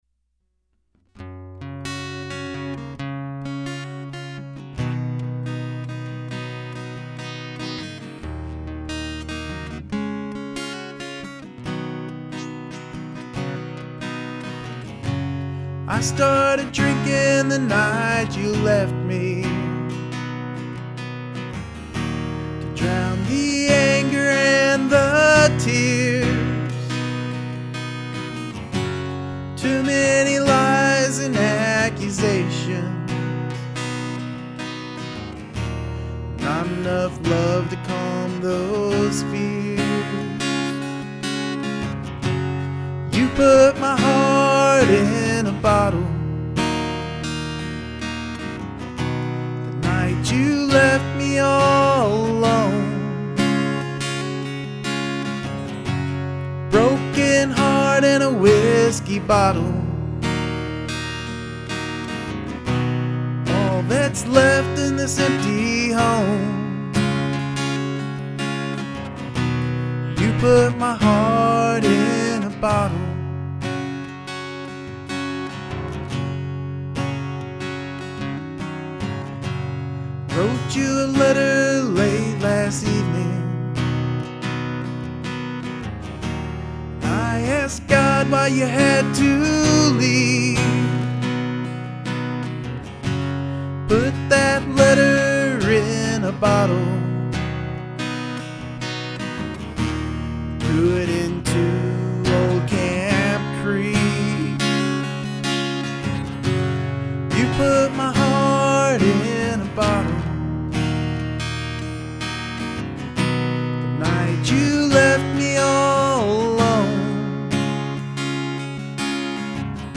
An hour later I have this song written and (roughly) recorded.
Your voice sounds great and fits your acoustic playing quite nicely !! !!